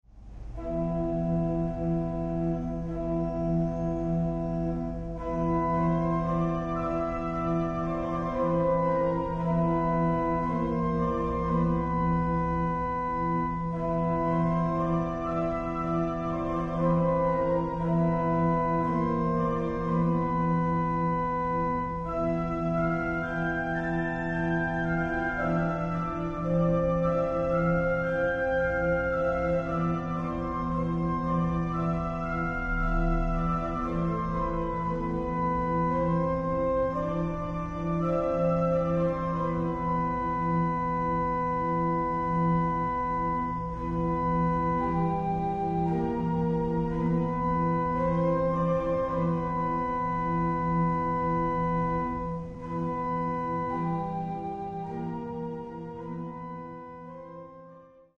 eseguite all'organo a canne
Organo costruito dai Fratelli Collino nel 1887 a Torino